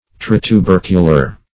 Search Result for " tritubercular" : The Collaborative International Dictionary of English v.0.48: Tritubercular \Tri`tu*ber"cu*lar\, a. (a) Having or designating teeth with three cusps or tubercles; tricuspid.